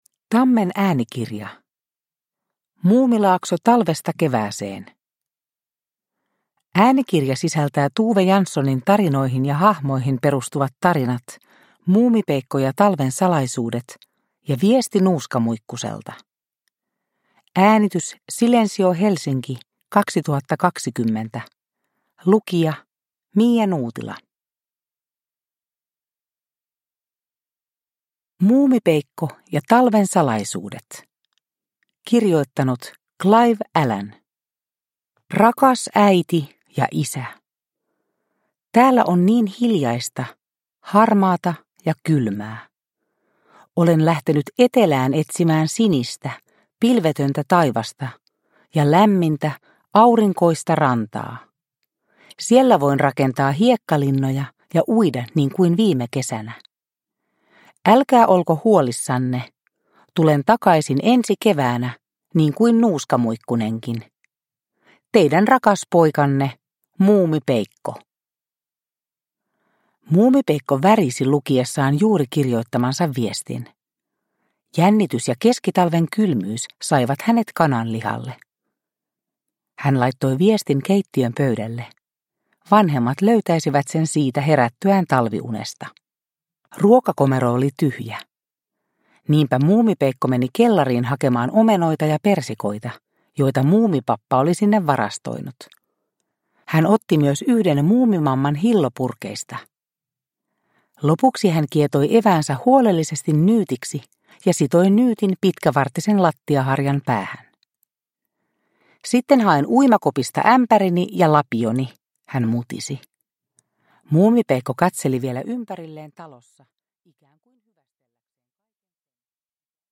Muumilaakso talvesta kevääseen – Ljudbok – Laddas ner